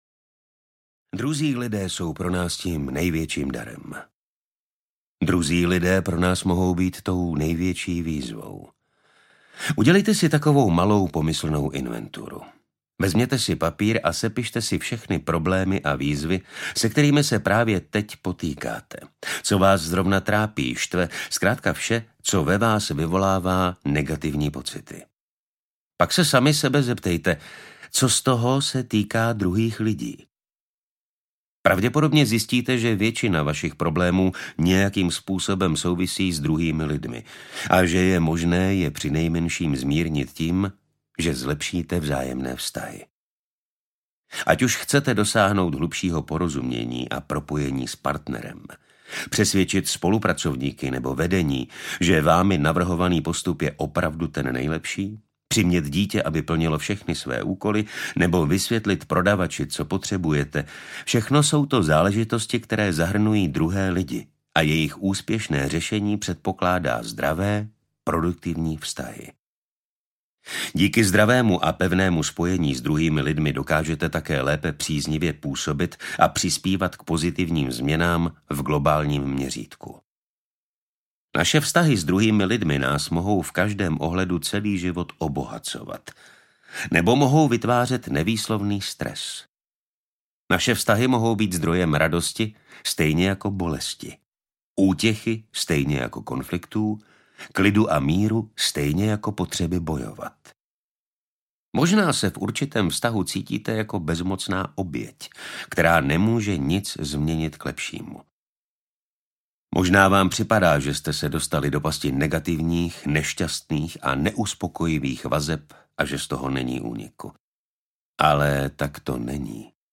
Audio verze nového bestselleru Willa Bowena.